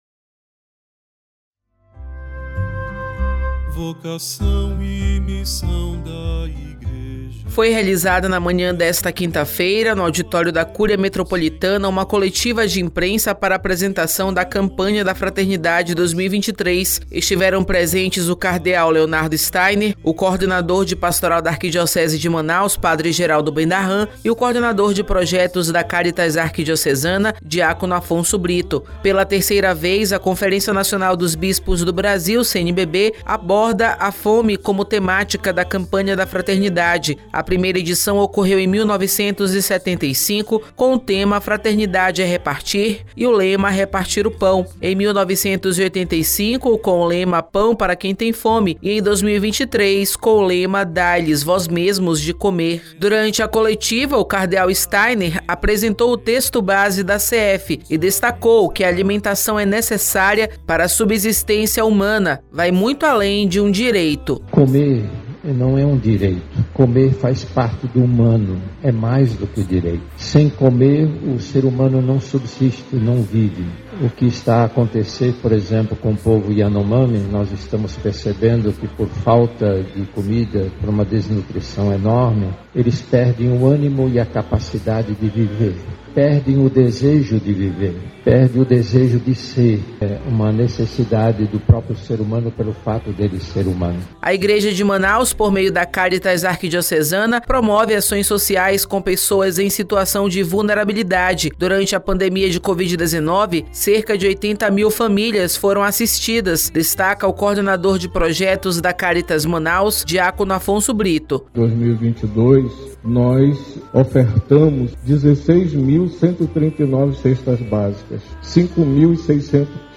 Foi realizada na manhã desta quinta-feira (16), no auditório da Cúria Metropolitana, uma coletiva de imprensa para apresentação da Campanha da Fraternidade (CF), 2023.